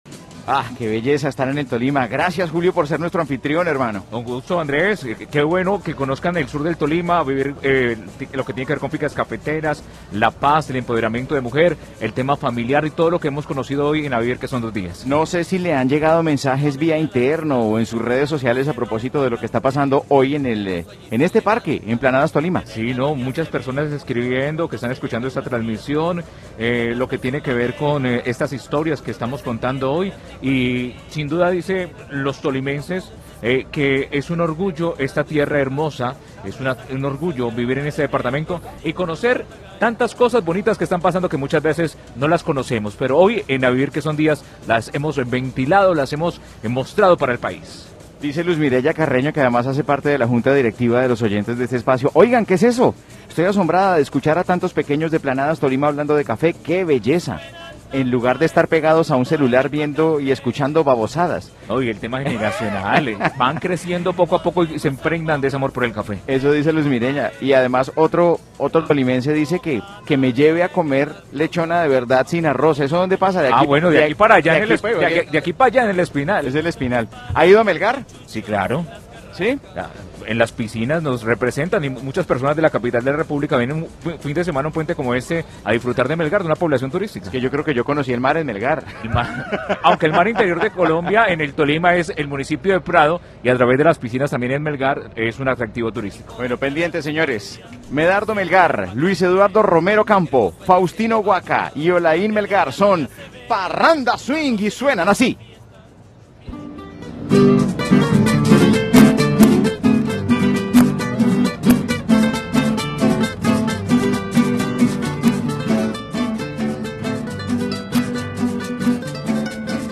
Requinto
Guacharaca